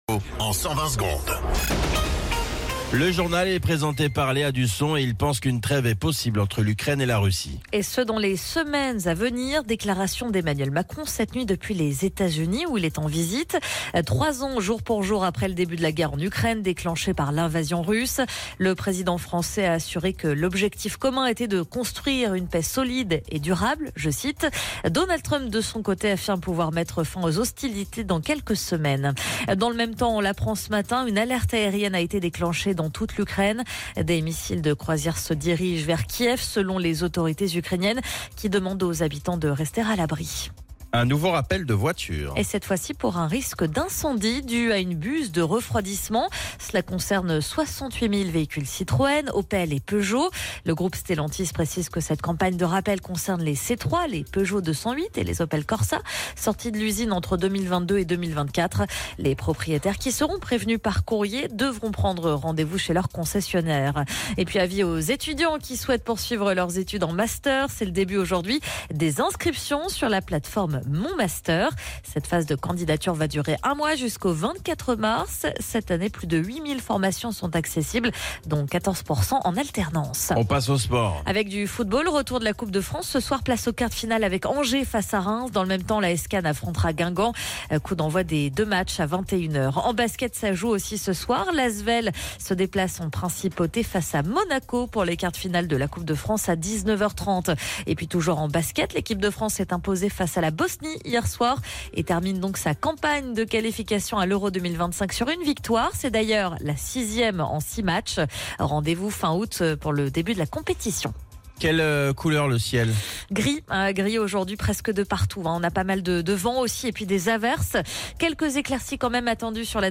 Flash Info National 25 Février 2025 Du 25/02/2025 à 07h10 .